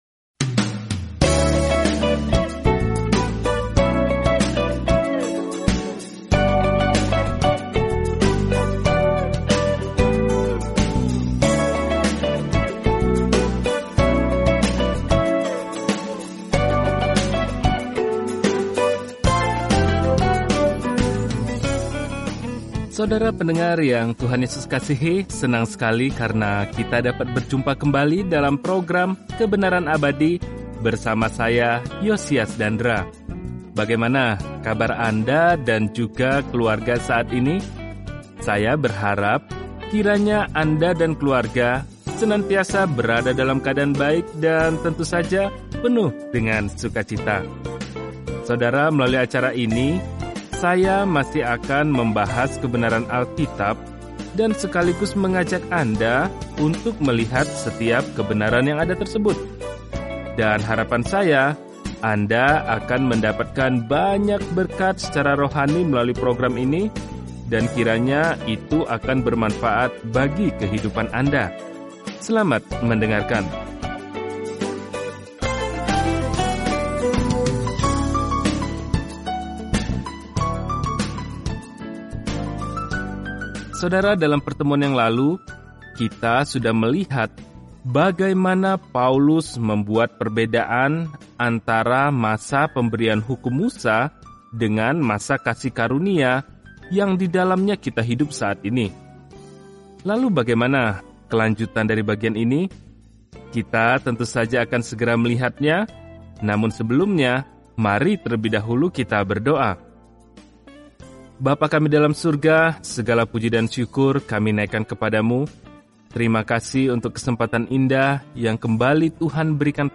Firman Tuhan, Alkitab 2 Korintus 3:11-18 Hari 4 Mulai Rencana ini Hari 6 Tentang Rencana ini Sukacita hubungan dalam tubuh Kristus disorot dalam surat kedua kepada jemaat di Korintus saat Anda mendengarkan pelajaran audio dan membaca ayat-ayat pilihan dari firman Tuhan.